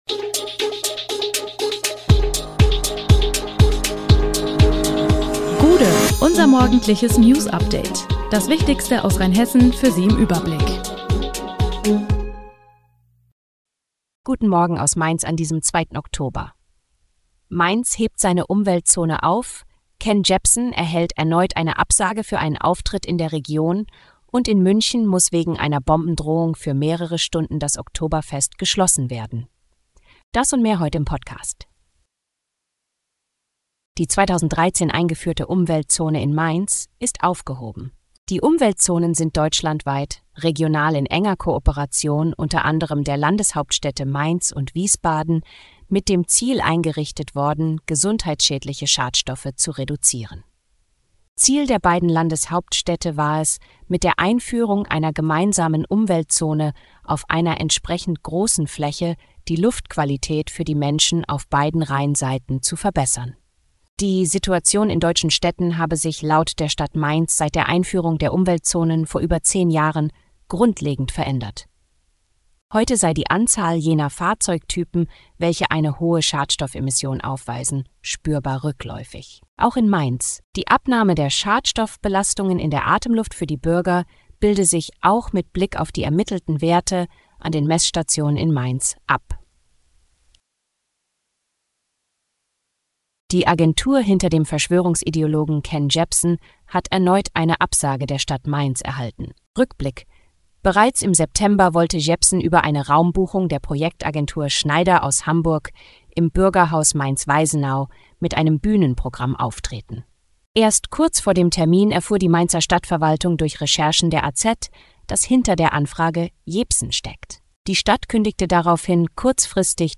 Der Podcast am Morgen für die Region
Nachrichten